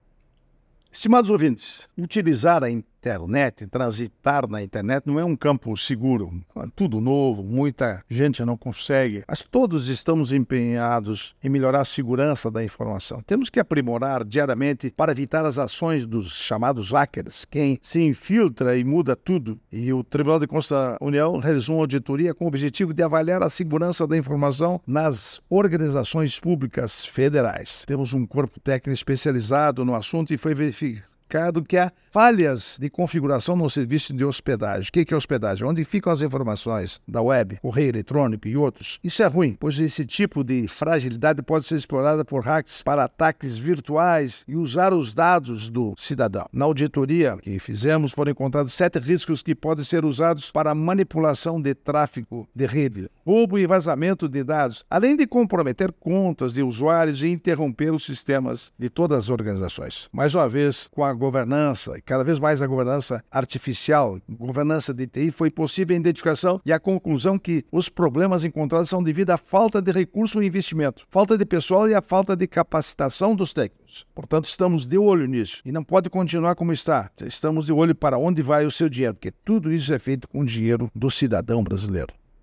É o assunto do comentário do ministro do Tribunal de Contas da União, Augusto Nardes, nesta sexta-feira (03/05/24), especialmente para OgazeteirO.